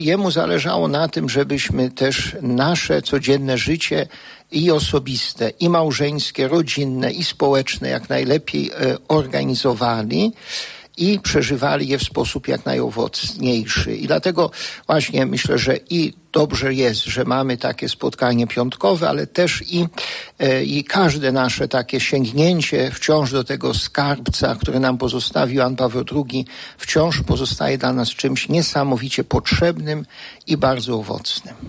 Uczestnicy dzisiejszej (13.09.) konferencji dotyczącej festiwalu 'Iskra Nadziei. Podlaskie w hołdzie Janowi Pawłowi II’ wspominali papieża Polaka.